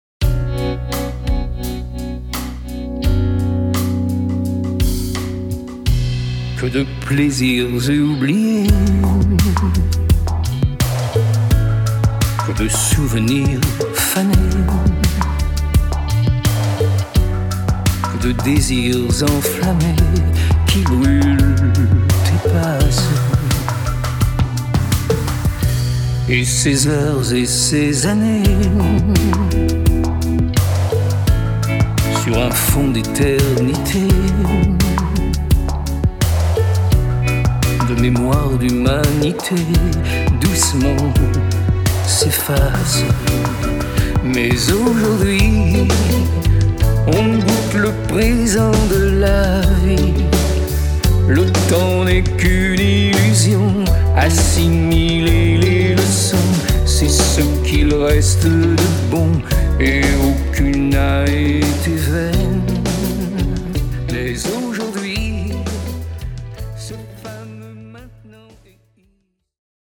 exprimées en ballades, jazz, bossa-novas, pop